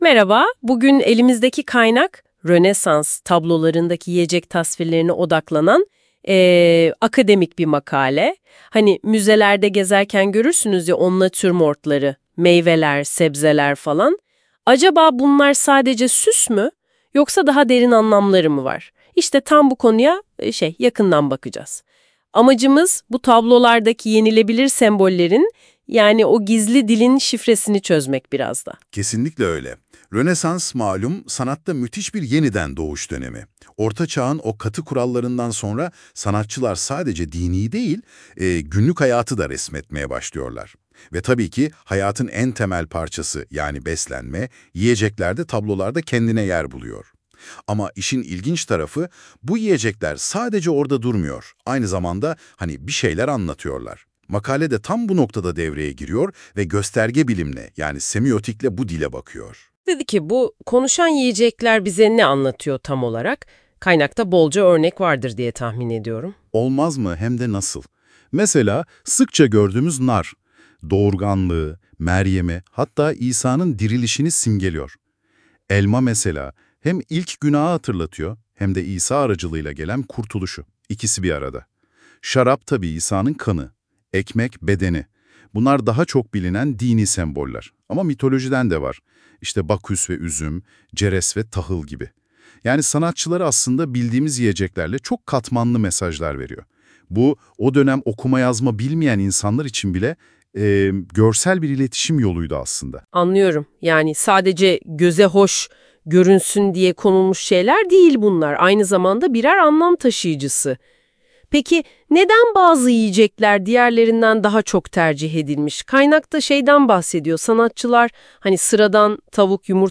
Rönesans Resminde Gastronomi Öğeleri Üzerine Söyleşi